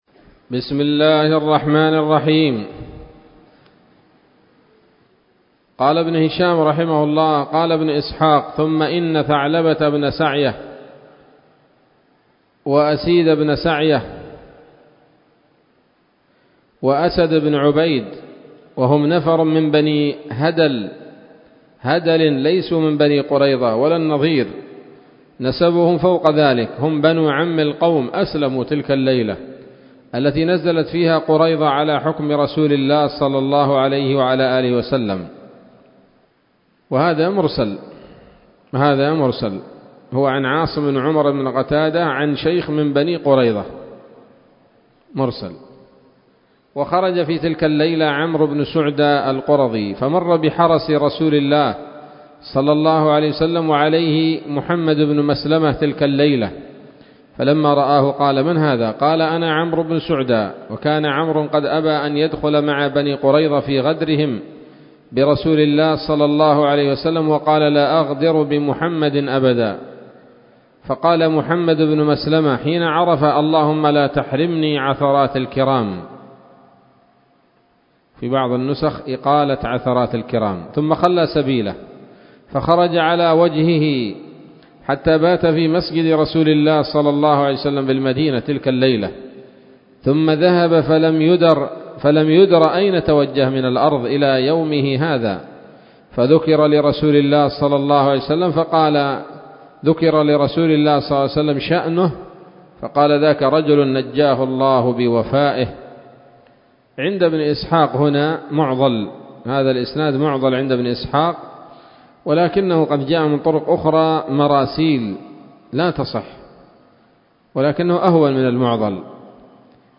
الدرس السابع بعد المائتين من التعليق على كتاب السيرة النبوية لابن هشام